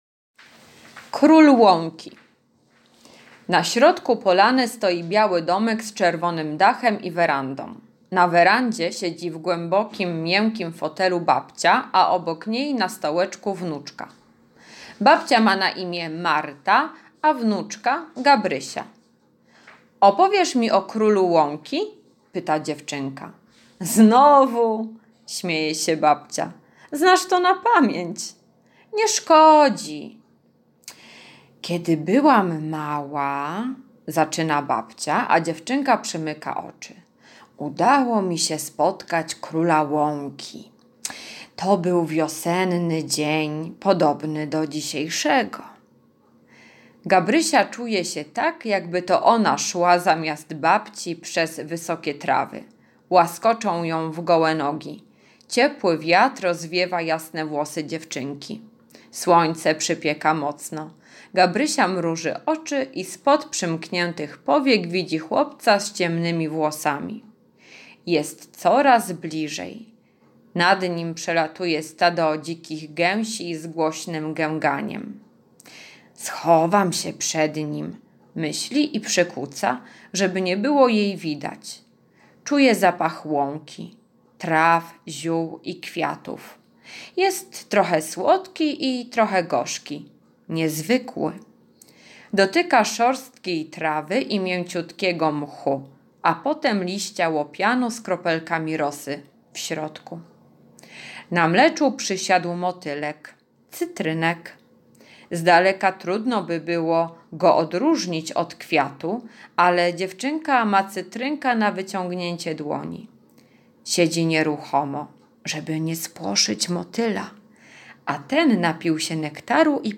bajka "Król łąki" [4.21 MB]